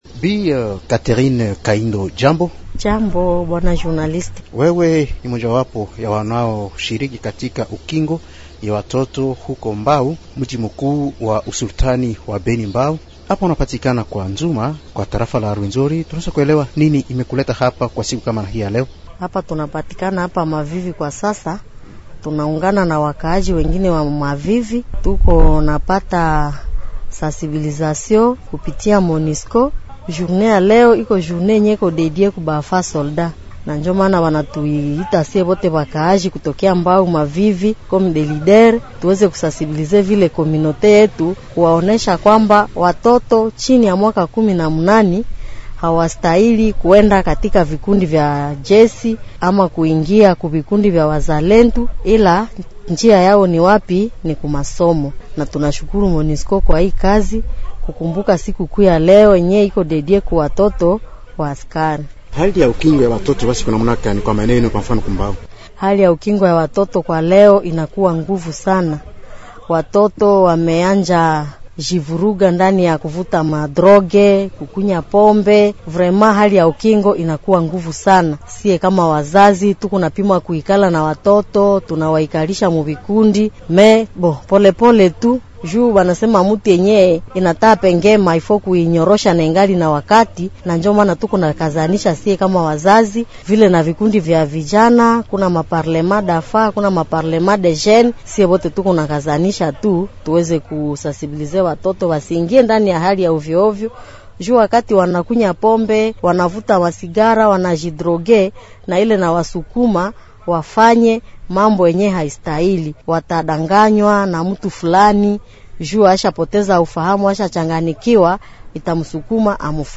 akiwa katika mazungumzo